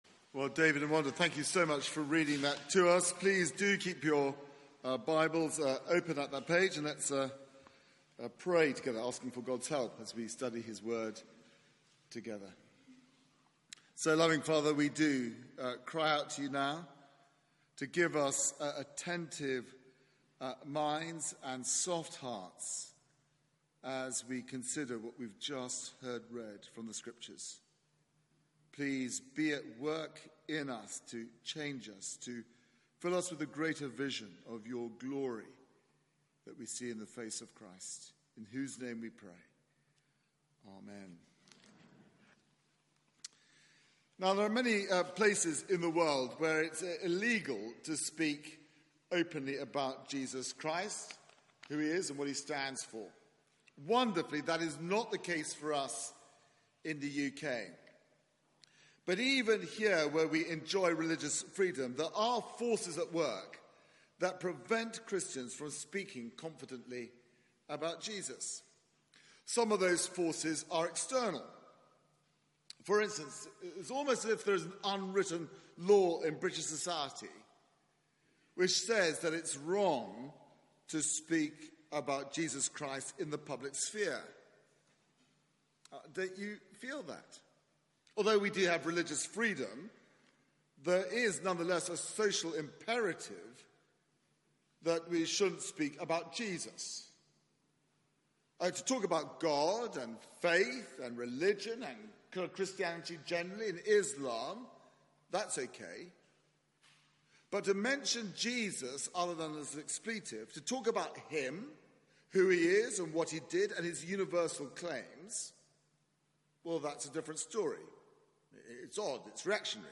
Media for 6:30pm Service on Sun 19th Aug 2018 18:30 Speaker
Passage: Acts 5:12-42 Series: To the ends of the earth Theme: Opposition Sermon